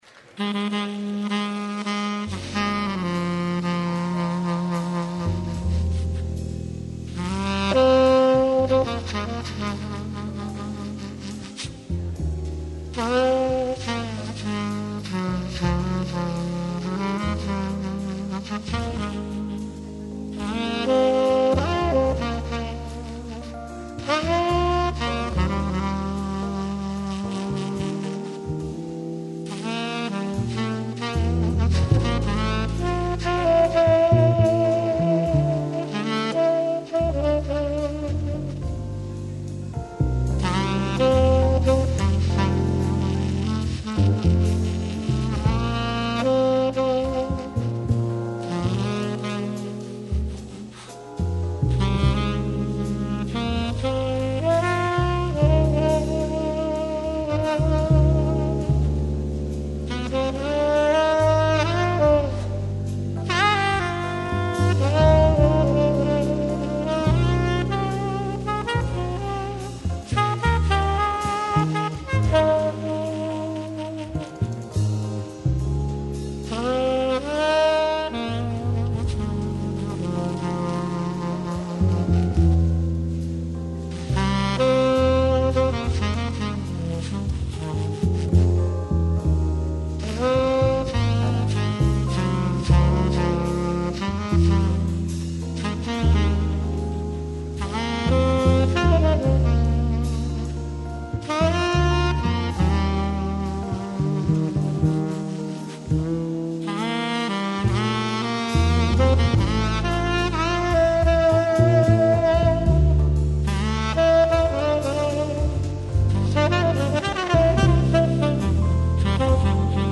Jazzová témata / Jazz Themes